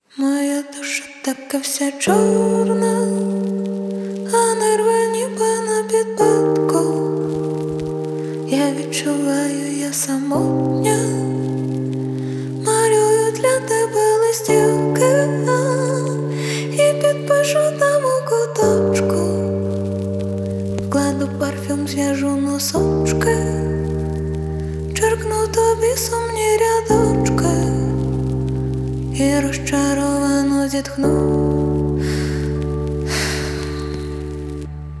Жанр: Поп / Инди / Русские
# Indie Pop